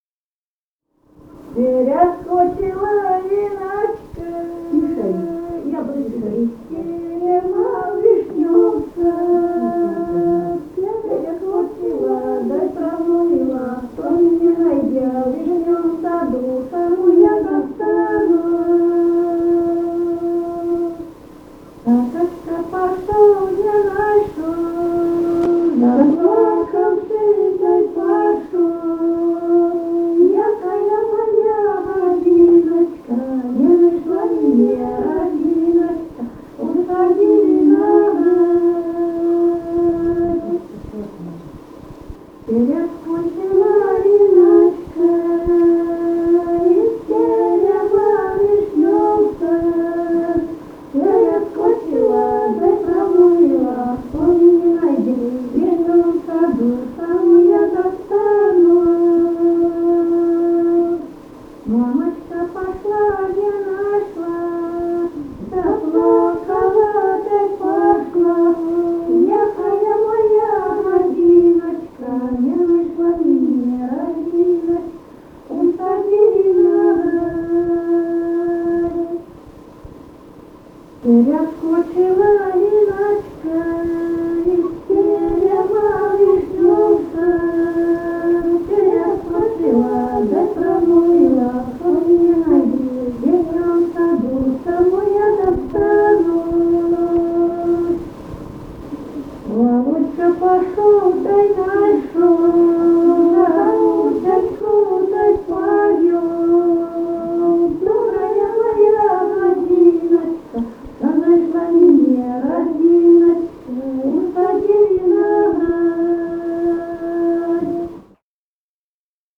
Музыкальный фольклор Климовского района 003. «Перескочила Ниночка» (свадебная).